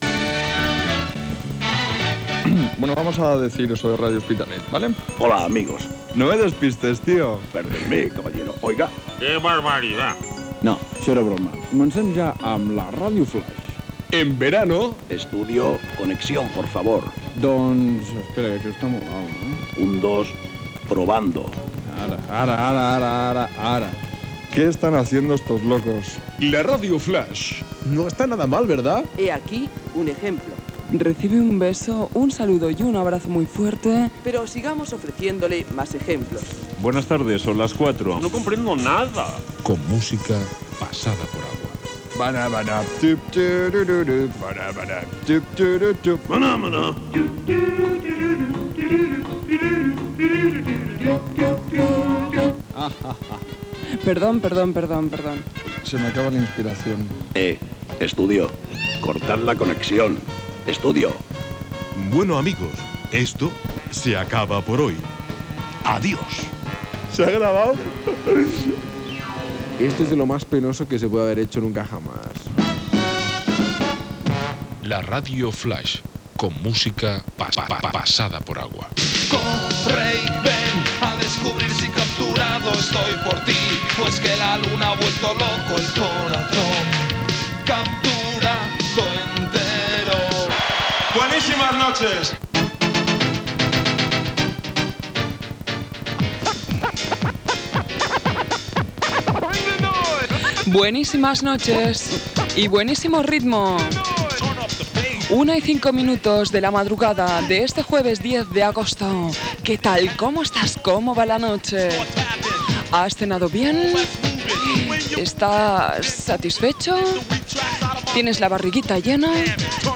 b5df744d56cd1e0d4030b0e2295e3cf320ce61eb.mp3 Títol Ràdio L'Hospitalet Emissora Ràdio L'Hospitalet Titularitat Pública municipal Nom programa La radio flash Descripció Final del programa i inici de la radiofórmula musical.